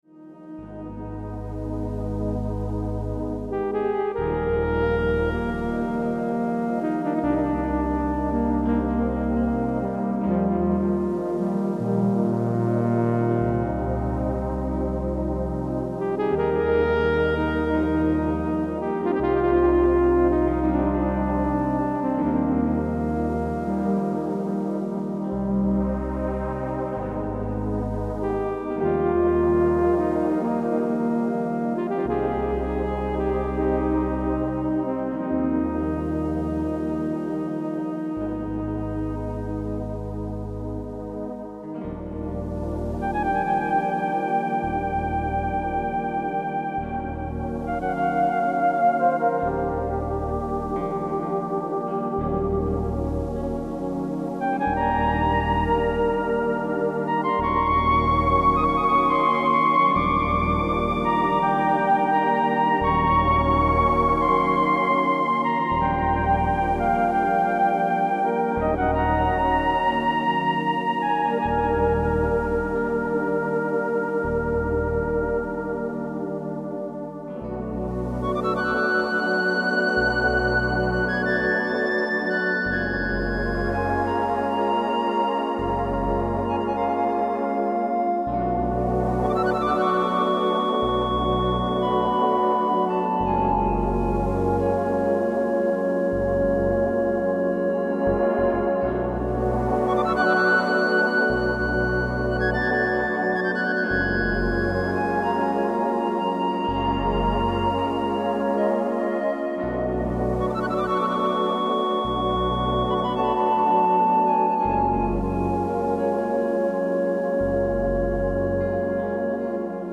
Music for relaxation and reflection